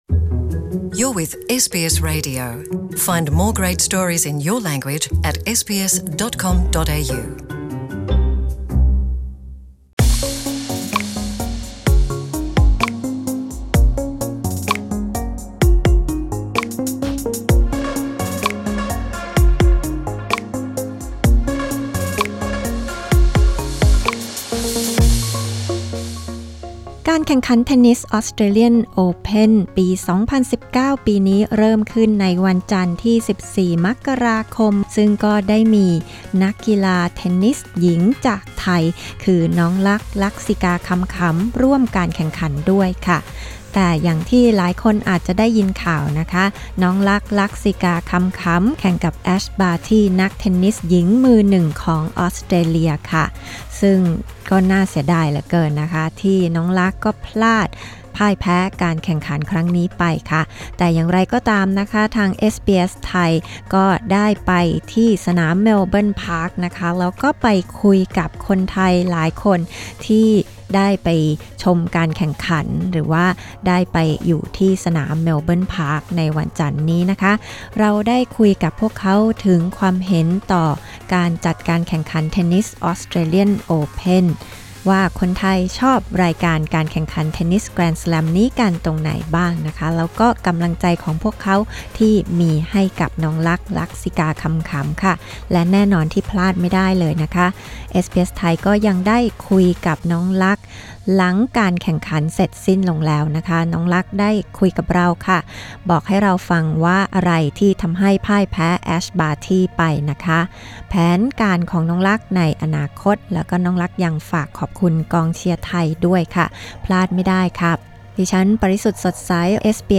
นักเทนนิสหญิง ลักษิกา คำขำ บอกกับเอสบีเอส ไทย ถึงสาเหตุหนึ่งที่ทำให้พ่ายแพ้แอช บาร์ตี จากออสเตรเลีย ในการแข่งขันเทนนิส ออสเตรเลียน โอเพน 2019 พร้อมคุยกับแฟนเทนนิสไทยว่าประทับใจอะไรในการแข่งขันรายการนี้